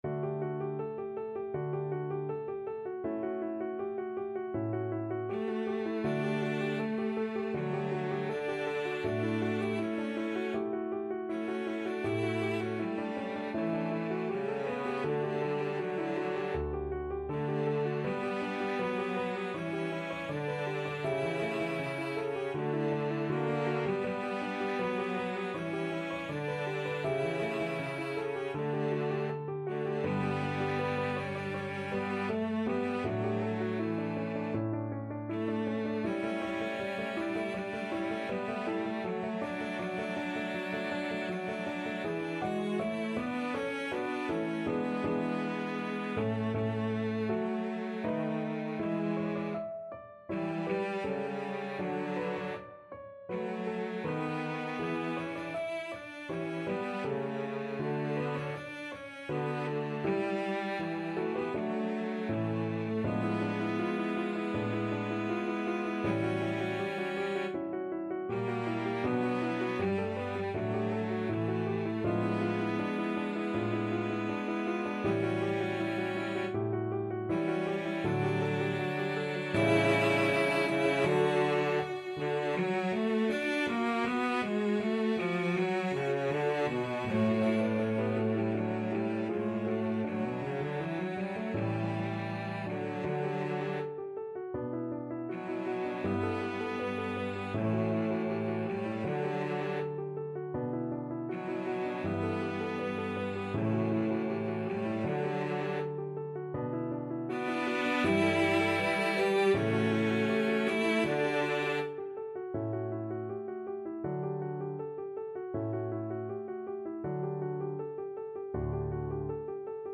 Cello 1Cello 2
Andante
Classical (View more Classical Cello Duet Music)